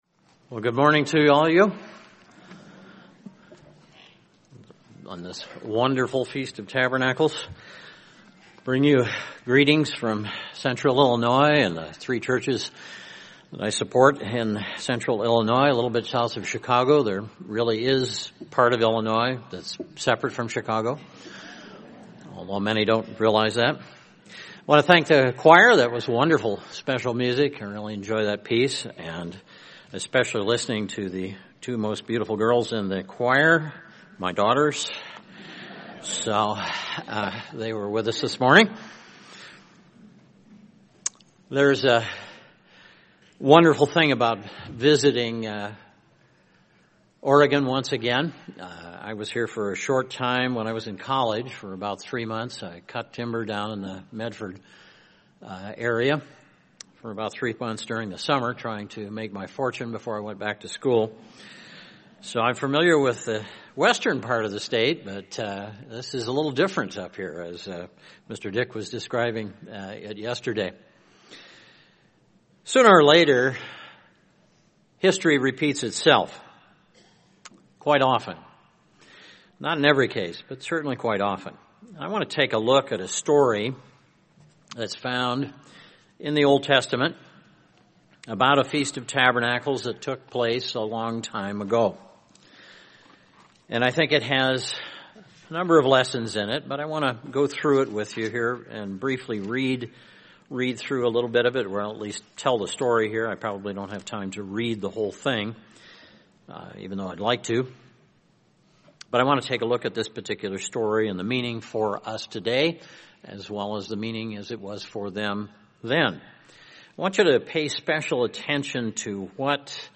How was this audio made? This sermon was given at the Bend, Oregon 2013 Feast site.